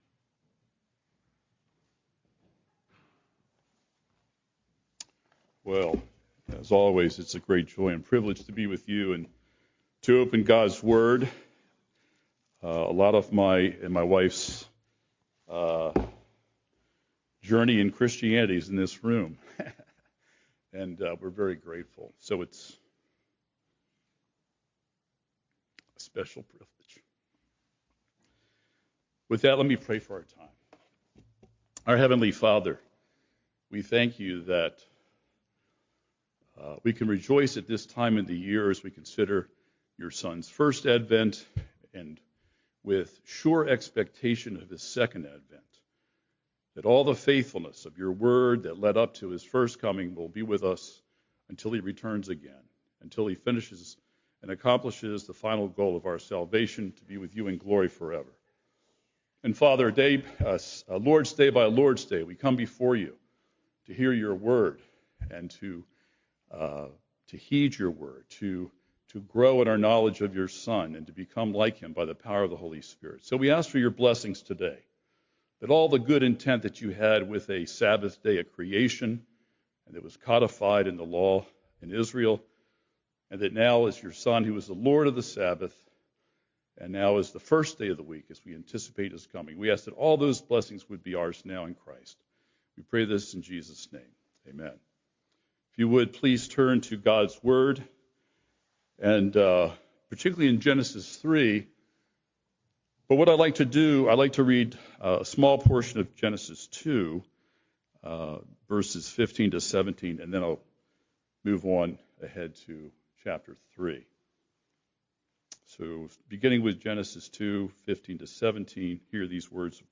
Discerment - The Need of Advent: Sermon on Genesis 3 - New Hope Presbyterian Church